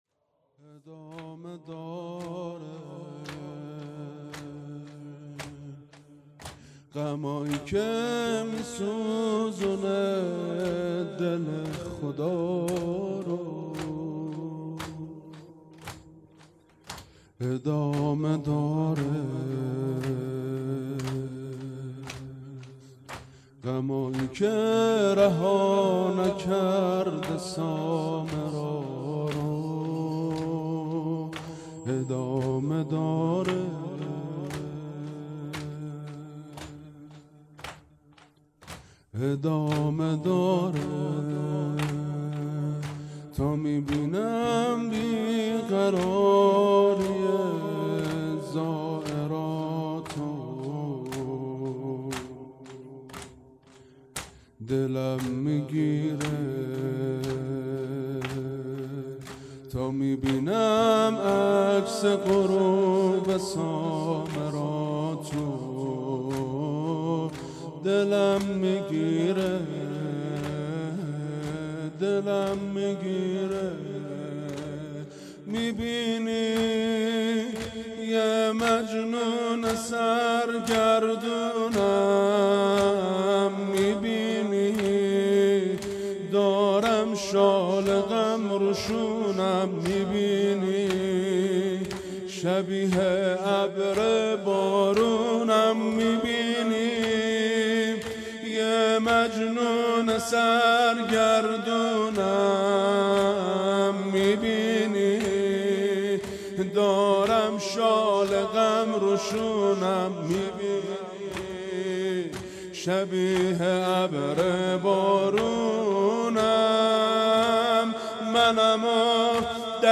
هيأت یاس علقمه سلام الله علیها